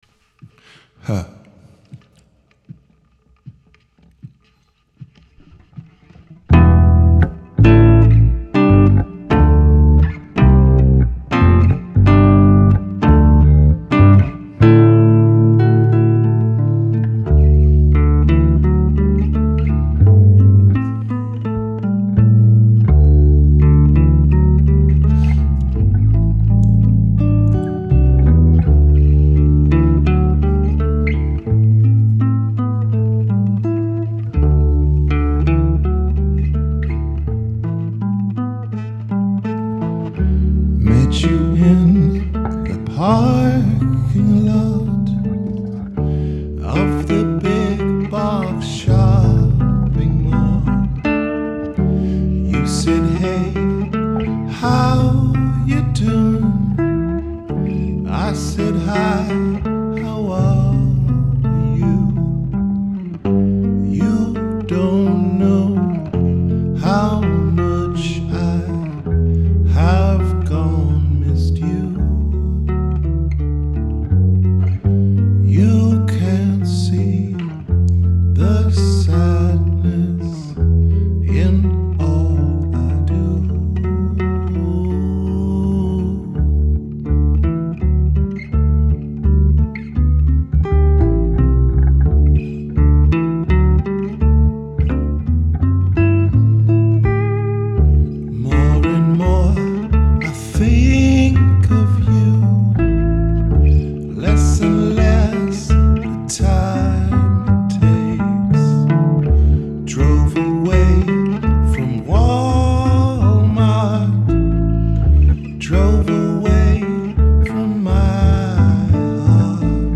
Rehearsals 27.2.2012